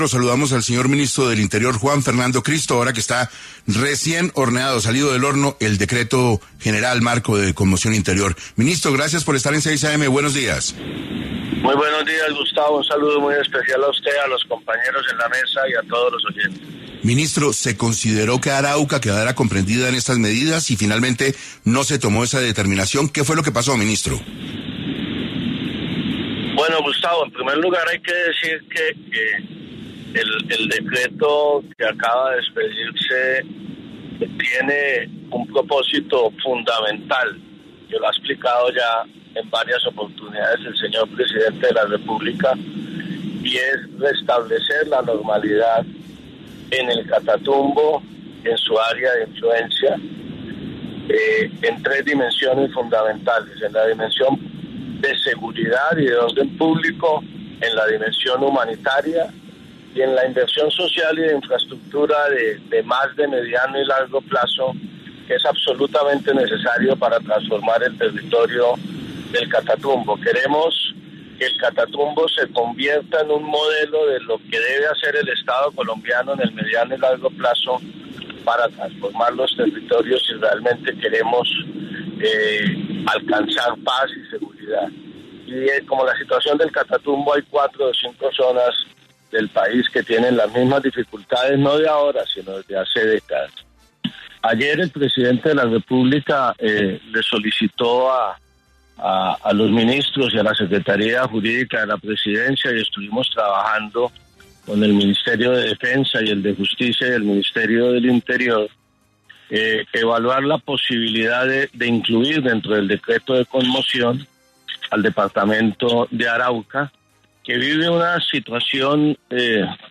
En 6AM de Caracol Radio estuvo el ministro del Interior, Juan Fernando Cristo, para hablar sobre qué abarca la conmoción interior del Gobierno ante los enfrentamientos en el Catatumbo y cuál es el propósito de la medida.